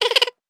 ihob/Assets/Extensions/CartoonGamesSoundEffects/Laugh_v1/Laugh_v2_wav.wav at master
Laugh_v2_wav.wav